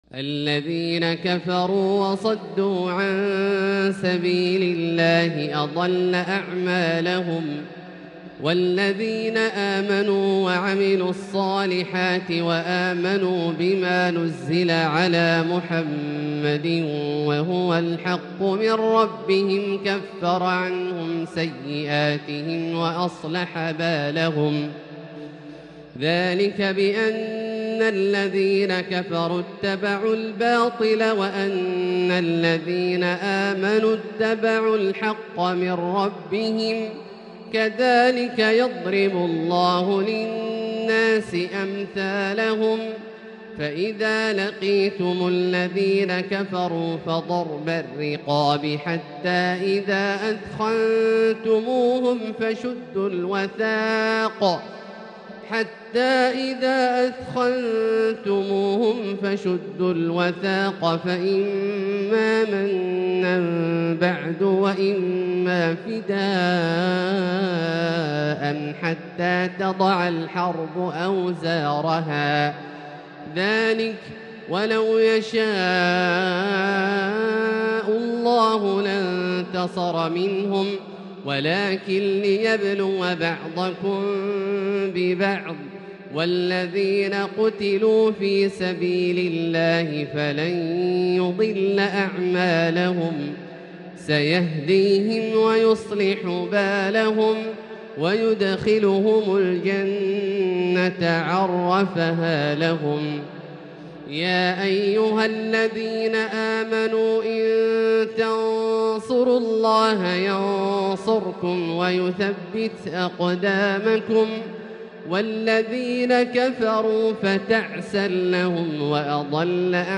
تلاوة ترنمية لـ سورة محمد كاملة للشيخ د. عبدالله الجهني من المسجد الحرام | Surat Muhammed > تصوير مرئي للسور الكاملة من المسجد الحرام 🕋 > المزيد - تلاوات عبدالله الجهني